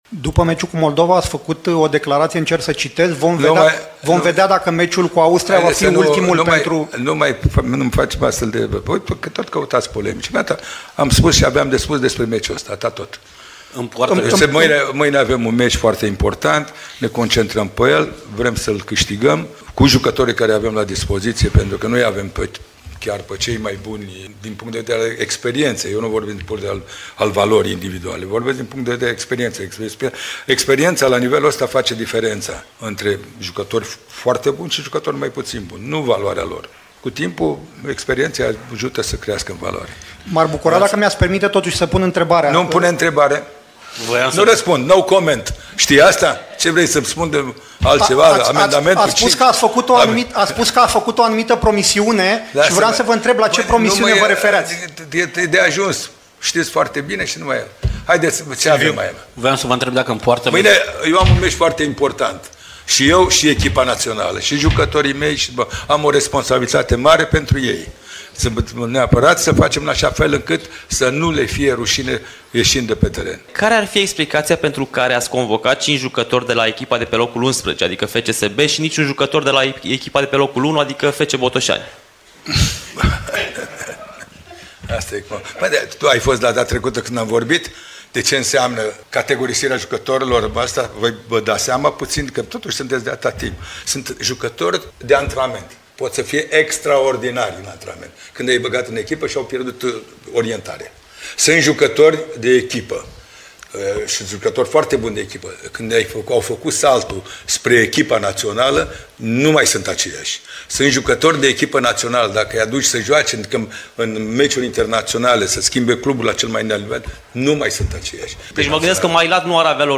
Selecționerul Mircea Lucescu a avut trei momente de iritare și chiar enervare, la conferința de presă de astăzi.
Lucescu-iritat.mp3